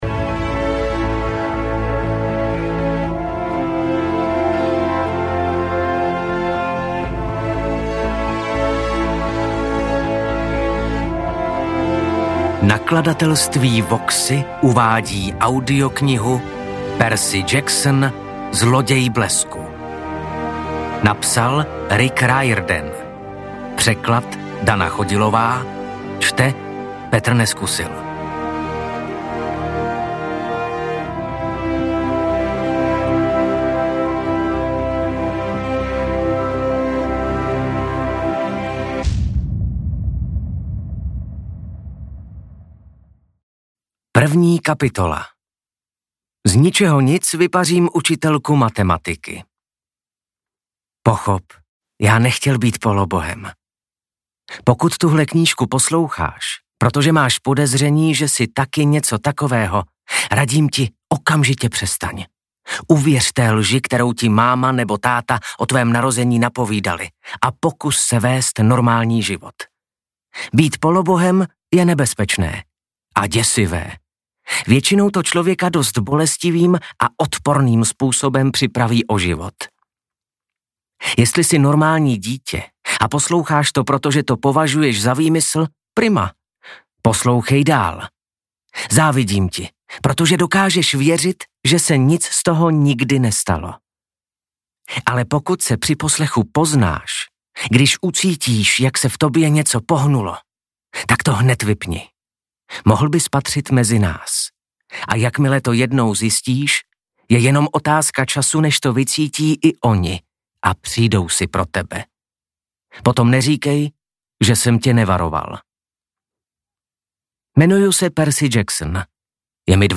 Audiokniha:
Skvěle namluvená audiokniha prvního dílu, kterou se velmi užívám, i když knihu jako takovou jsem četl už několikrát.